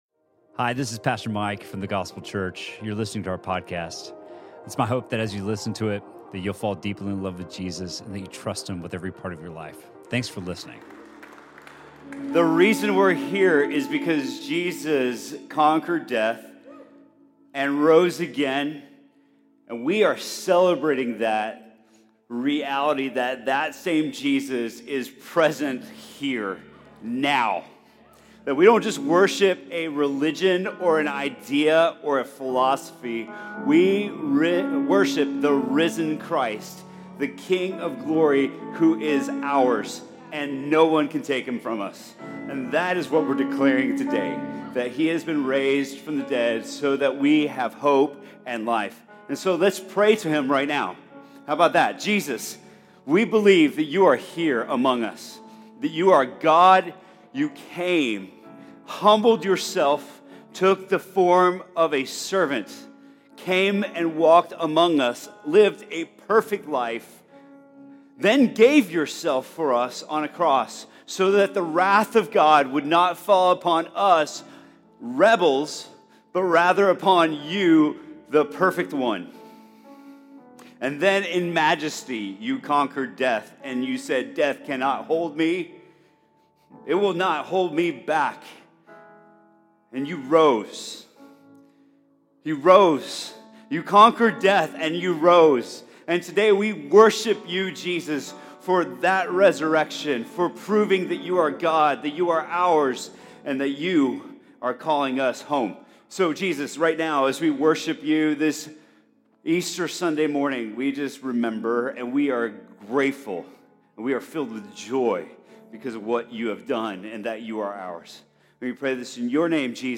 Sermon from The Gospel Church on April 21st, 2019.